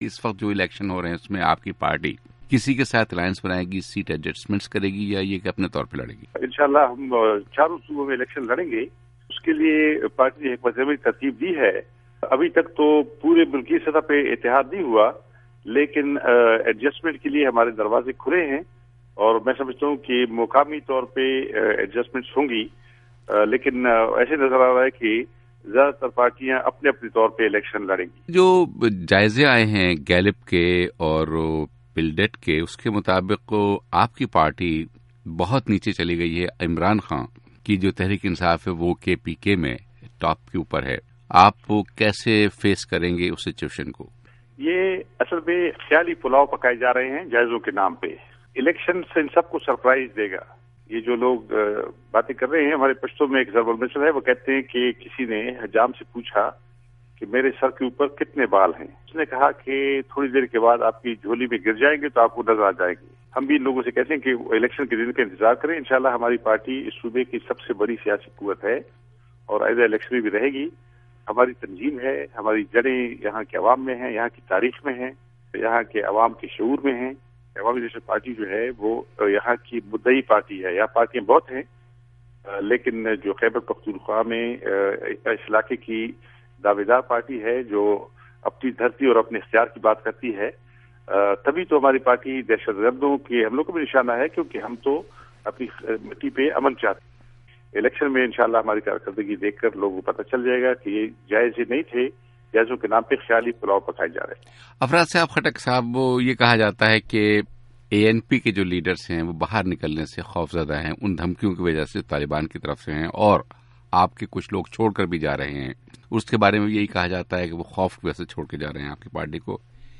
’وائس آف امریکہ‘ کے ساتھ ایک حالیہ انٹرویو میں، ایک سوال کا جواب دیتے ہوئےاُنھوں نے کہا کہ اُن کی پارٹی کا ابھی تک ملکی سطح پر اتحاد نہیں ہوا۔
افراسیاب خٹک کا انٹرویو